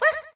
toadette_wah.ogg